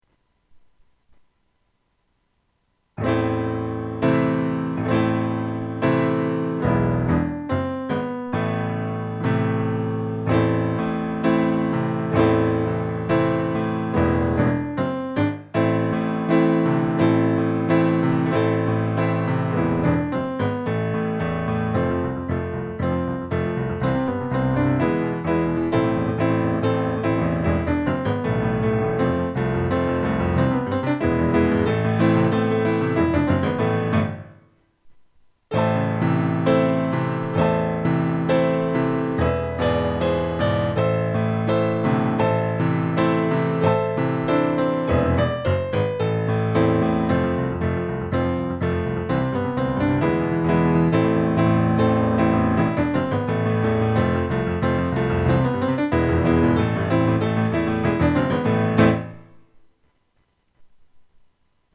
Když jsem přemýšlel na tím, jaké zpívání si pro vás pro tento týden připravím a myslel jsem na to, že jste mi psali, že písničky, které jsem vám nahrál 6.4., jste si zpívali s chutí a dokonce je zpívali i někteří vaši sourozenci a rodiče, rozhodl jsem se, že pro vás nahraji doprovody několika dalších písní.
Přeji vám a případně i vaši sourozencům a rodičům pěkné zpívání a nezapomeňte vždy na vnímání předehry.